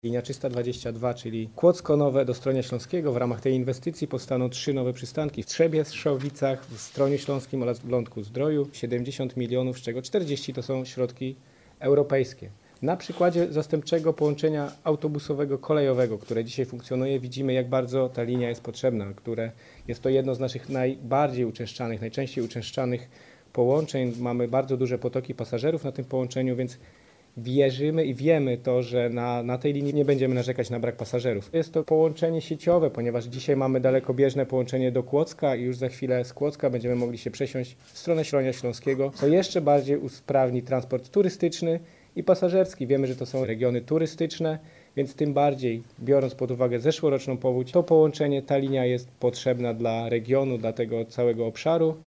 – Rewolucja kolejowa na Dolnym Śląsku trwa – zaznacza Michał Rado, wicemarszałek Województwa Dolnośląskiego.